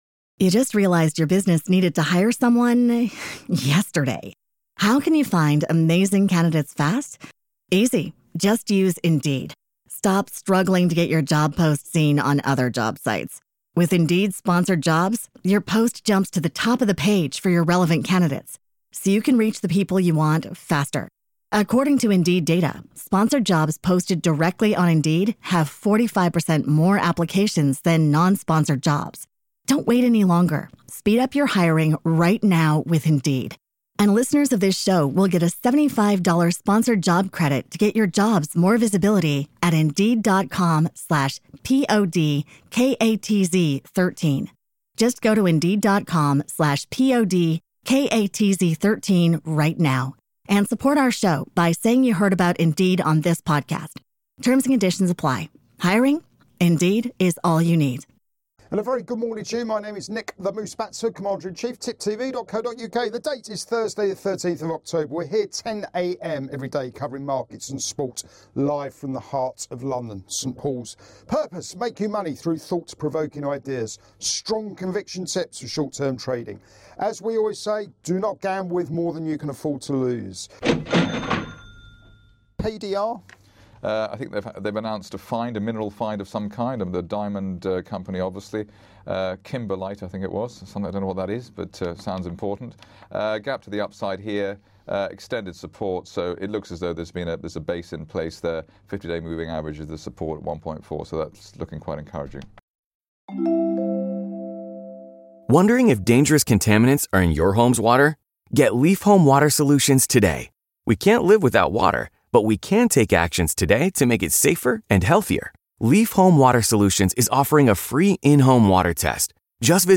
Live Market Round-Up